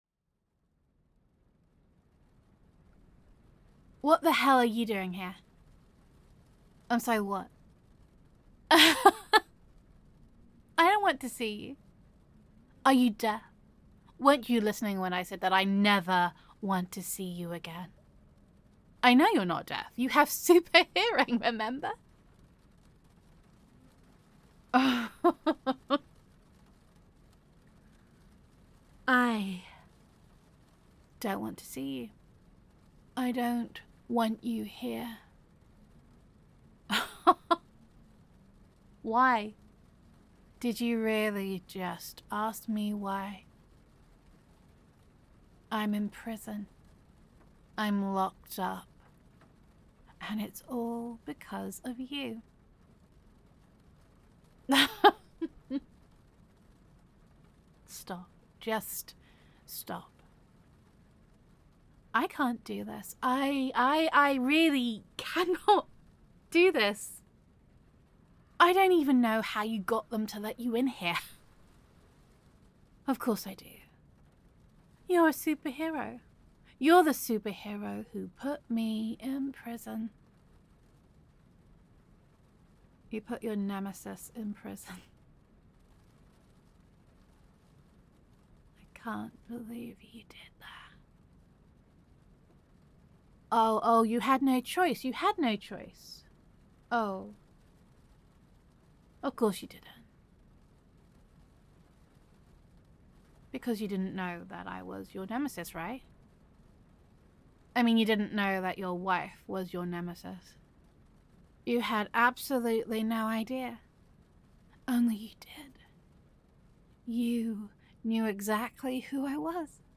[F4A] Betrayed [Superhero Listen][Good Intentions][Deception][Betrayal][Supervillain Roleplay][Engagement][Traumatised Villain][You Broke Me][Manic Laughter][Gender Neutral][You Thought You Were Doing the Right Thing, But Things Are So Much Worse Now]